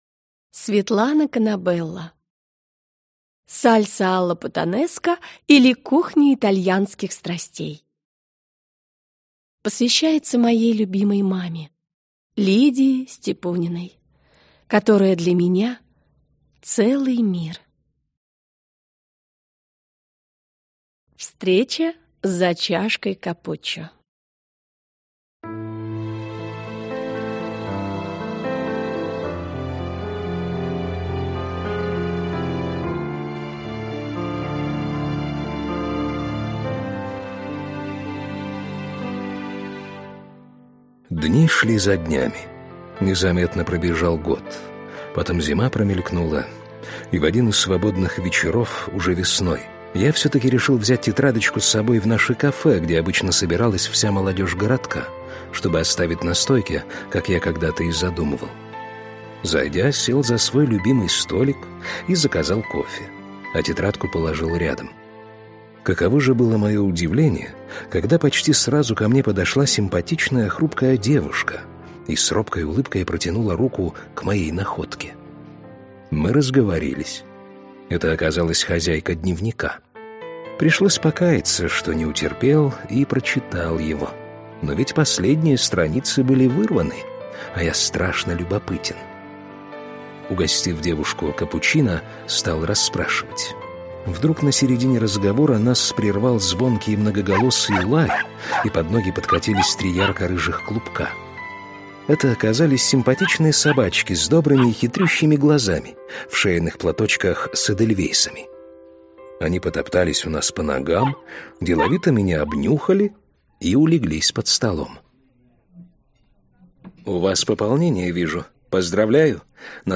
Аудиокнига Сальса алла путанеска, или Кухня итальянских страстей | Библиотека аудиокниг